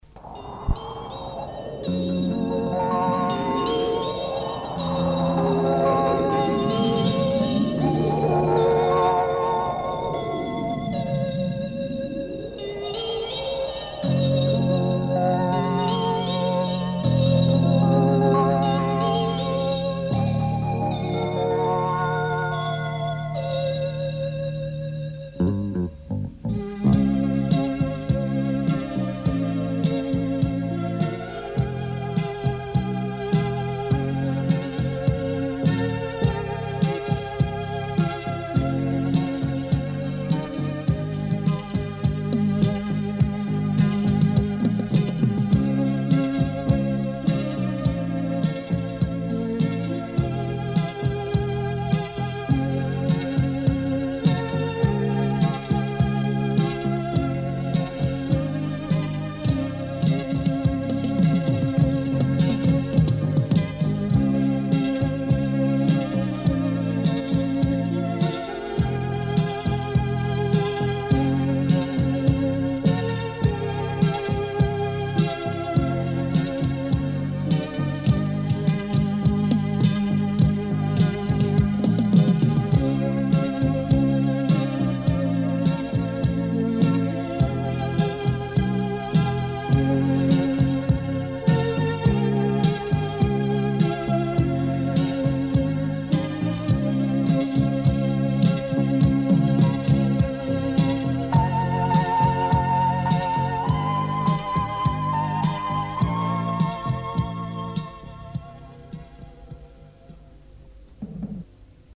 Помогите пожалуйчта опознать инструменталы
1 трек оцифрован с кассеты
попробуйте через программу шазам....судя по-всему первая точно не чистый инструментал...